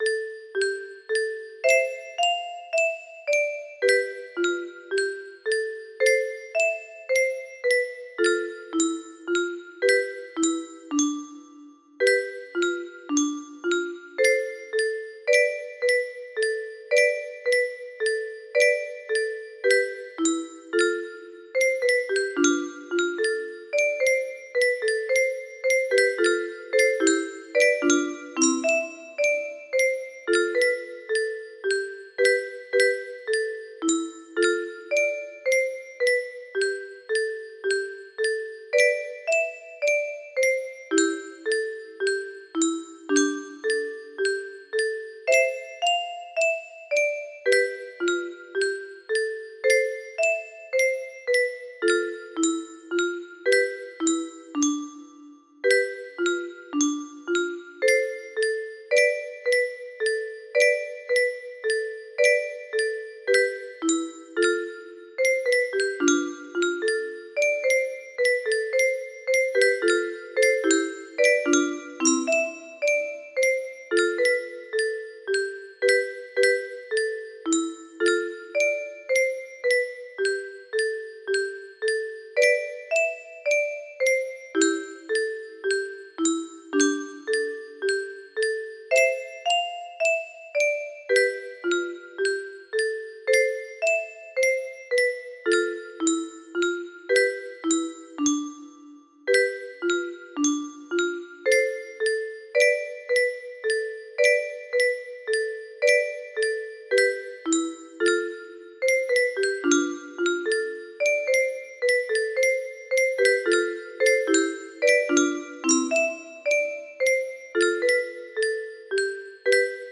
The storm that passed music box melody